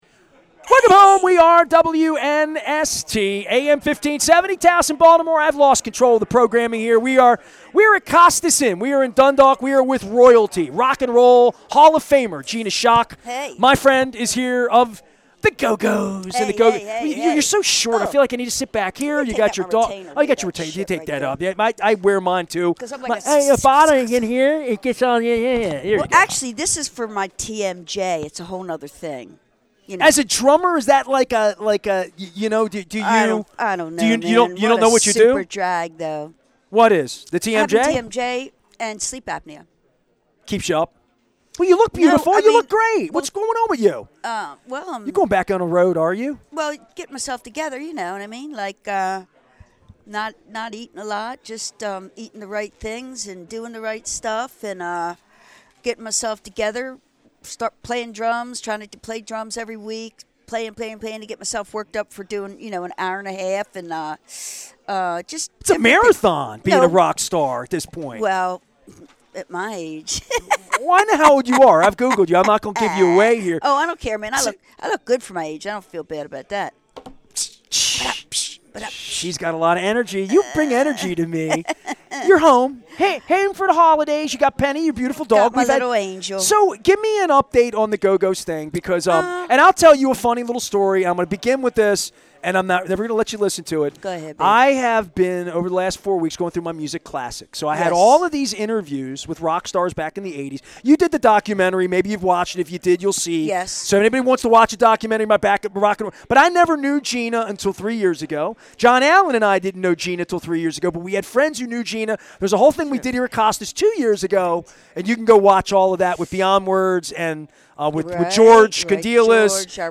Intelligent conversation about all things Baltimore.